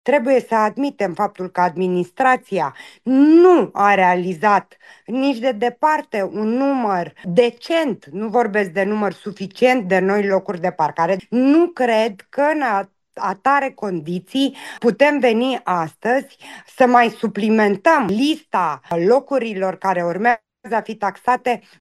Consilierul PSD Roxana Iliescu a reclamat că municipalitatea nu asigură suficiente locuri de parcare pentru a extinde actualul sistem de taxare.